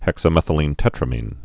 (hĕksə-mĕthə-lēn-tĕtrə-mēn)